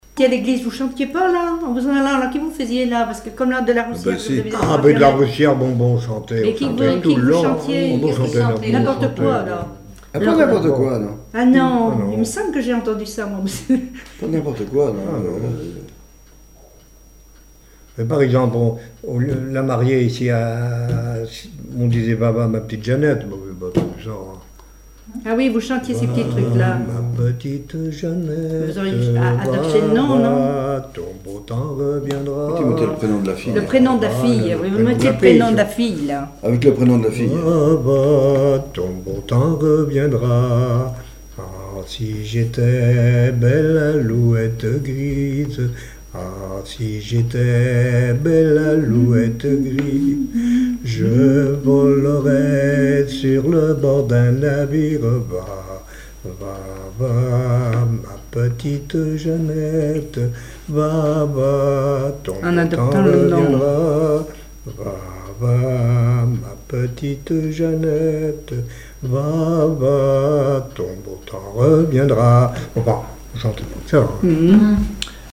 Genre laisse
Témoignages et chansons
Catégorie Pièce musicale inédite